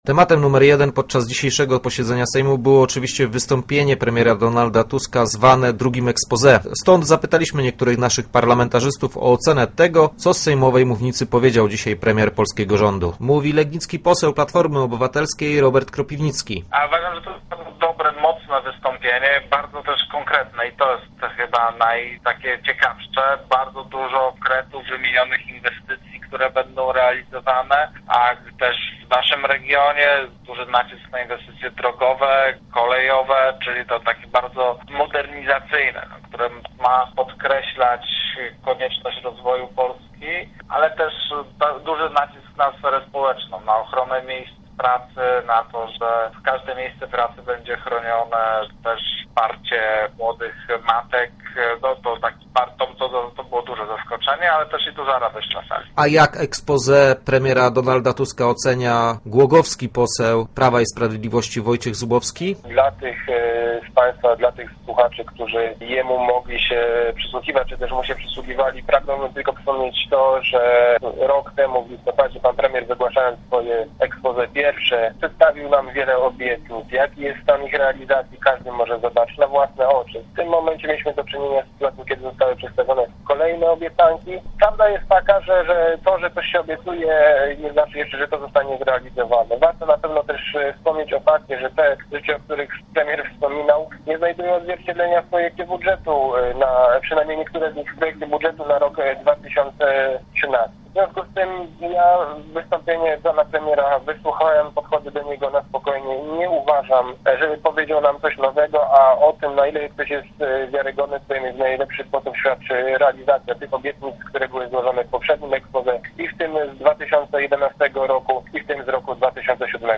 "Kolejne obiecanki bez pokrycia", mówi z kolei poseł Prawa i Sprawiedliwości, Wojciech Zubowski z Głogowa. "Pan premier się wypalił, nie był przekonywujący, nie tryskał energią, mówił bez wiary", ocenia natomiast lubiński poseł Sojuszu Lewicy Demokratycznej, Ryszard Zbrzyzny.